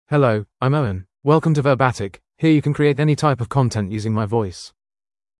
MaleEnglish (United Kingdom)
Owen is a male AI voice for English (United Kingdom).
Voice sample
Owen delivers clear pronunciation with authentic United Kingdom English intonation, making your content sound professionally produced.